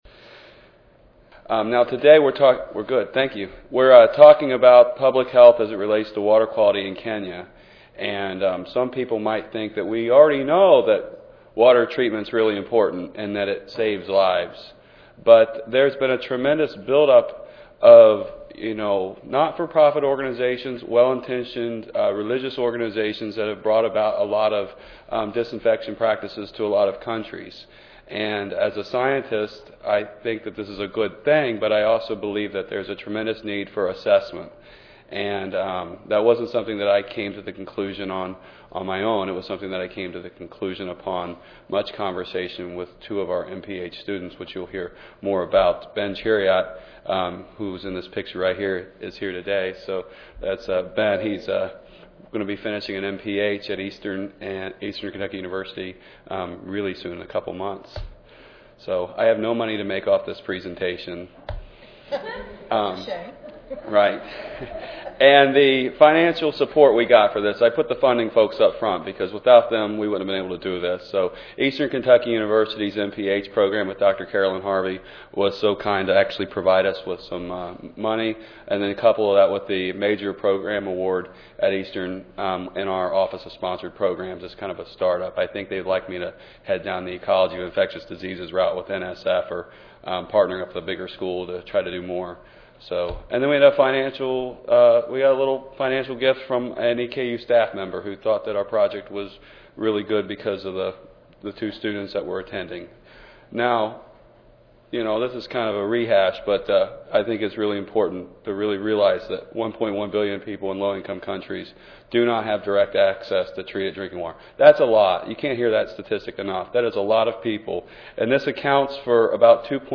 141st APHA Annual Meeting and Exposition (November 2 - November 6, 2013): International environmental health issues